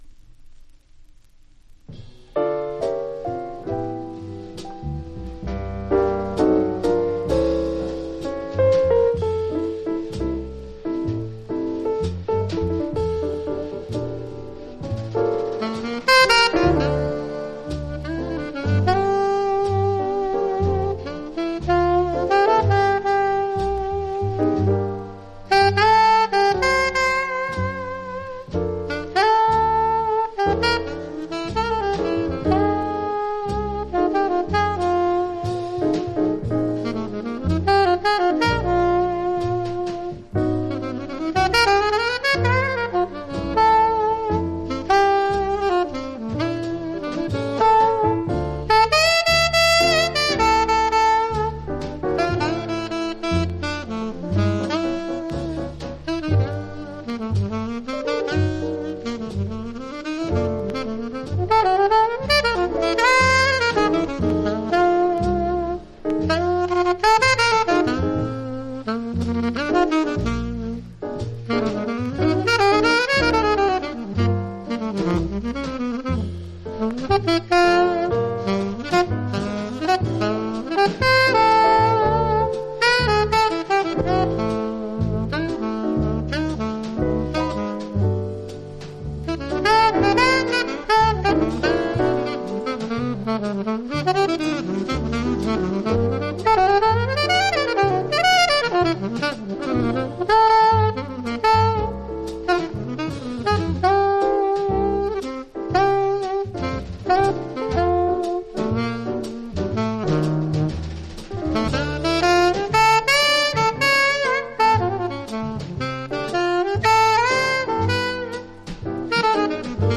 （B-3 プレス・小傷によりチリ、プチ音あり）
Genre US JAZZ